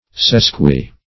Sesqui- \Ses`qui-\ [L., one half more, one and a half.] (Chem.)